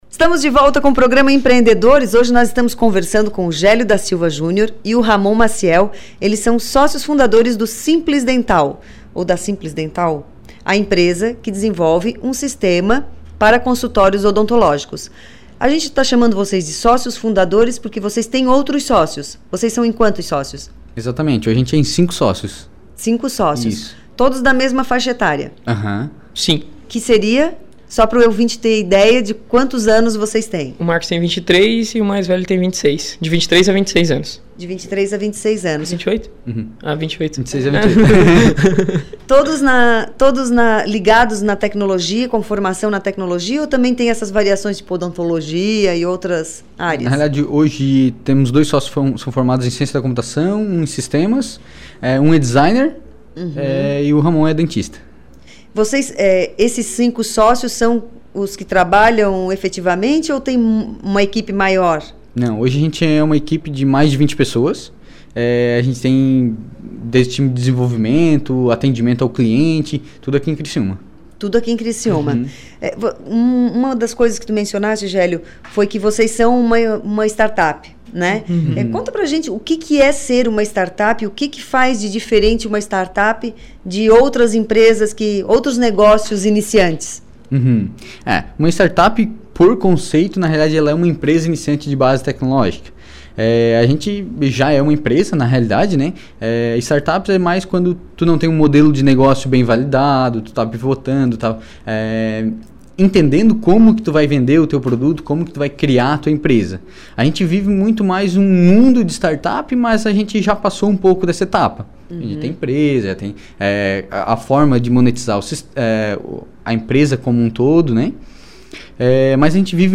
Entrevista
O Programa Empreendedores é veiculado originalmente na Rádio Som Maior.